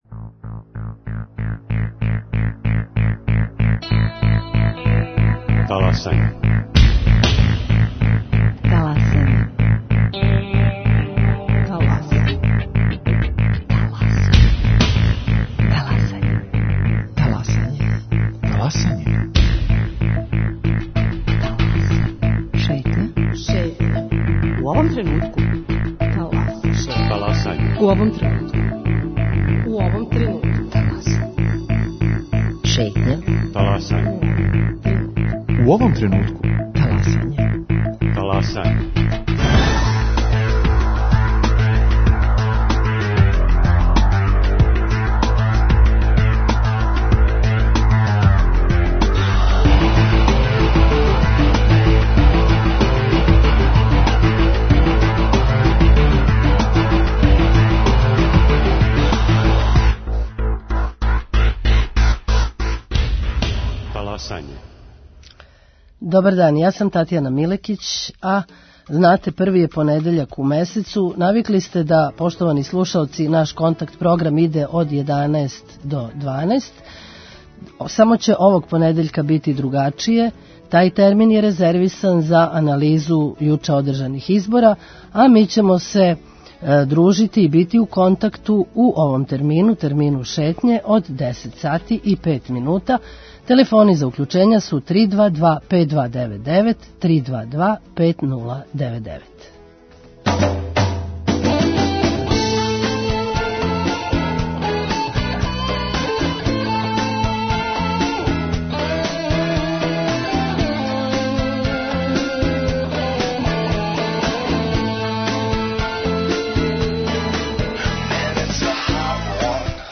Као и сваког првог понедељка у месецу, и данас вам пружамо прилику да 'таласате' заједно са нама. Ви причате, сугеришете, коментаришете - ми слушамо!